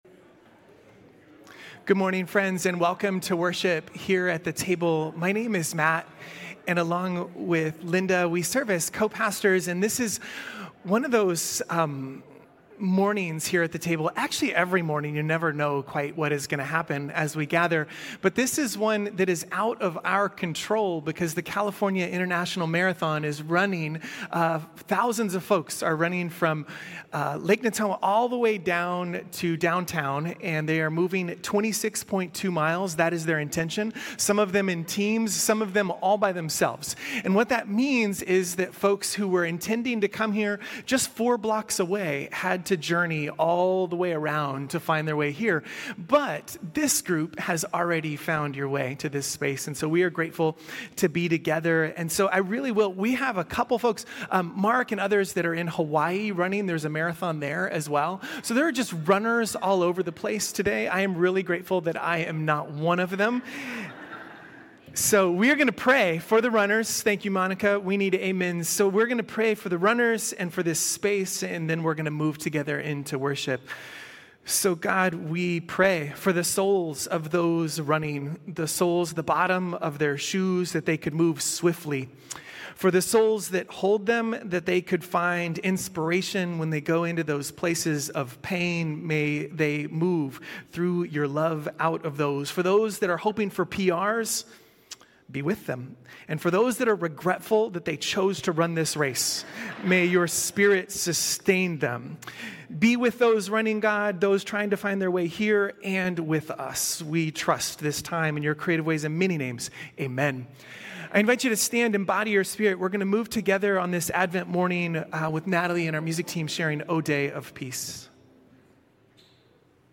Worship this morning at The Table holds this reading of Ruth alongside the quilters of Gee’s Bend in inviting us to rethink the ways God longs for us to create communities of justice and care beyond convention and with a critical eye to systemic forms of oppression which always already threaten our wounded world and fragmented connections.